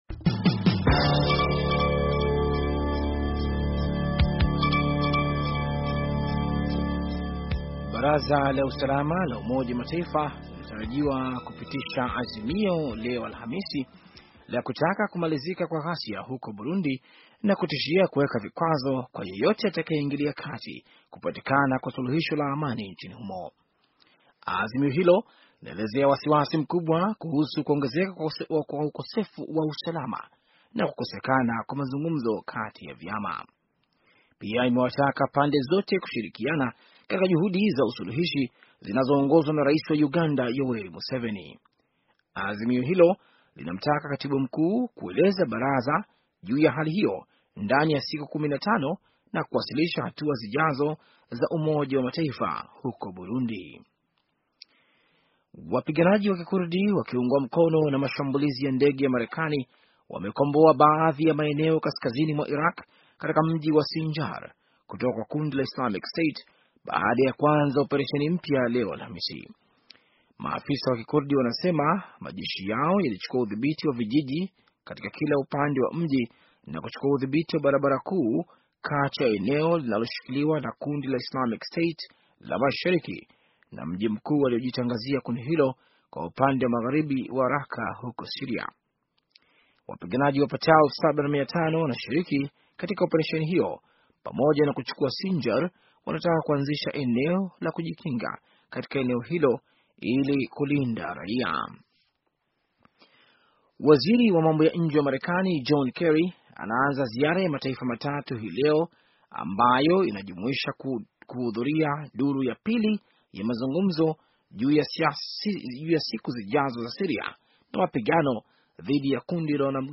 Taarifa ya habari - 5:23